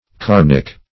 Search Result for " carnic" : The Collaborative International Dictionary of English v.0.48: Carnic \Car"nic\ (k[aum]r"n[i^]k), a. [L. caro, carnis, flesh.] Of or pertaining to flesh; specif.